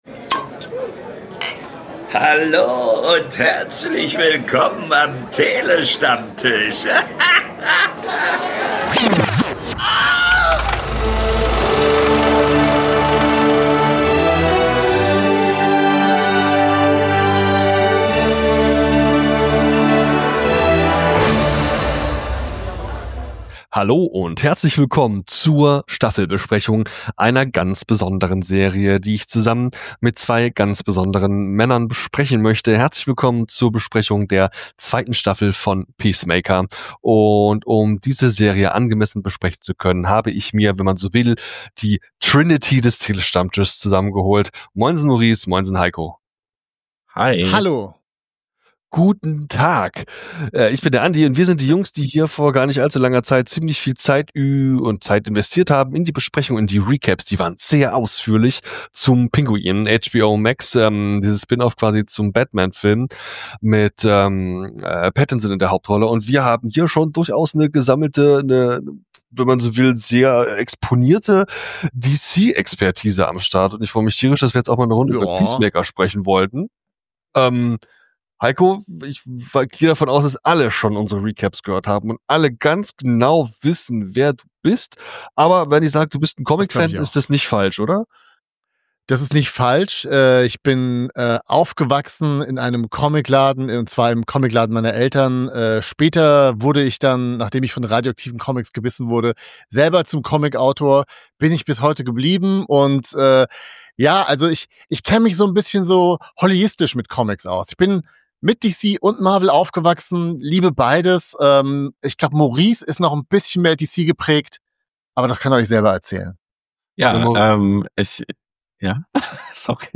Wir liefern euch launige und knackige Filmkritiken, Analysen und Talks über Kino- und Streamingfilme und -serien - immer aktuell, informativ und mit der nötigen Prise Humor. Website | Youtube | PayPal | BuyMeACoffee Großer Dank und Gruß für das Einsprechen unseres Intros geht raus an Engelbert von Nordhausen - besser bekannt als die deutsche Synchronstimme Samuel L. Jackson!